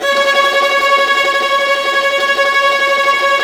Index of /90_sSampleCDs/Roland LCDP13 String Sections/STR_Violas FX/STR_Vas Tremolo